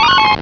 Cri de Farfuret dans Pokémon Rubis et Saphir.